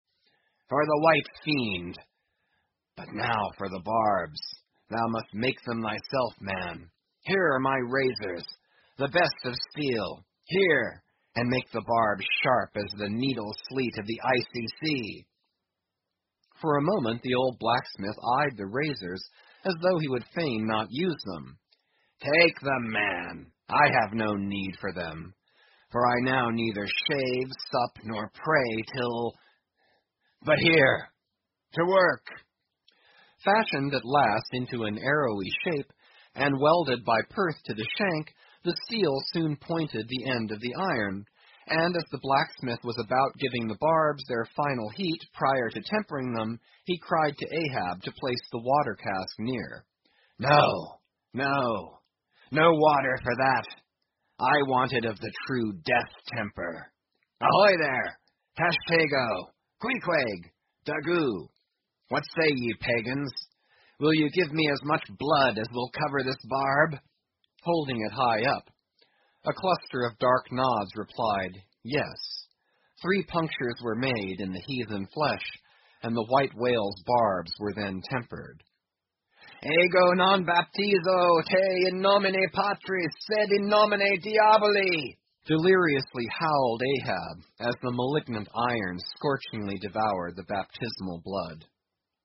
英语听书《白鲸记》第920期 听力文件下载—在线英语听力室